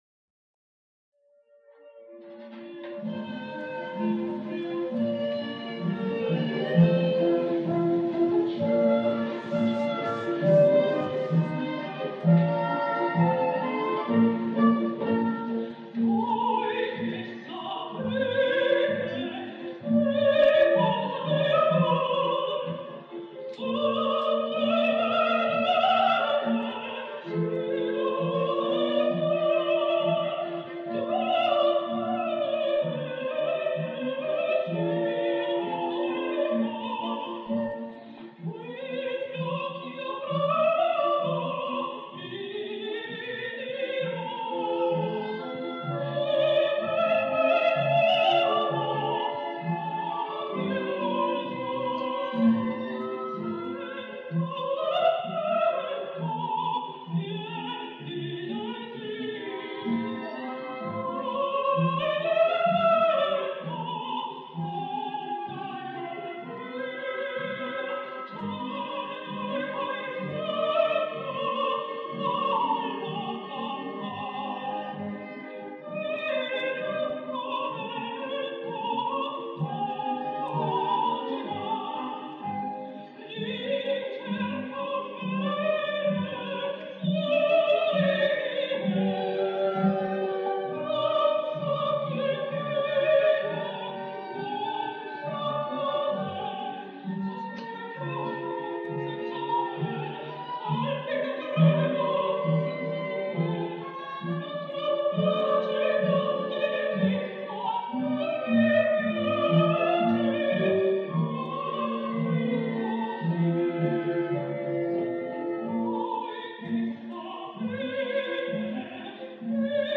mezzosoprano